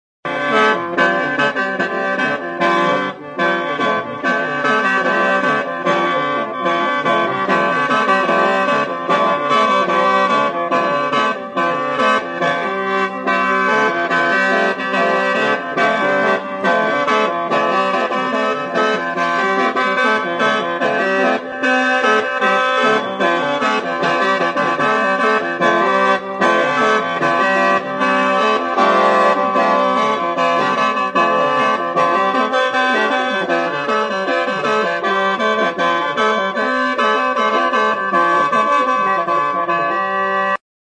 Instrumentos de músicaKHAEN
Aerófonos -> Lengüetas -> Libre
MOUTH-ORGAN.
ASIA -> THAILANDIA
Aho organoa da. Mihi sinple eta libreak dituzten kanaberazko tutuez osatua dago. 16 tutu ditu, paraleloki bi lerrotan jarriak.